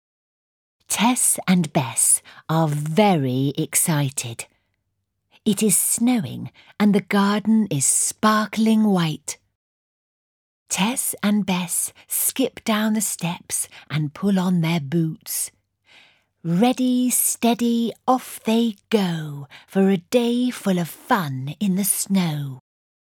Read by award winning actress Sophie Thompson.
Tess and Bess in the Snow is full of sound play with a special focus on ‘s’ and ‘s’blends.
Sophie’s lovely clear voice and engaging style is perfect for listening to Early Soundplay stories.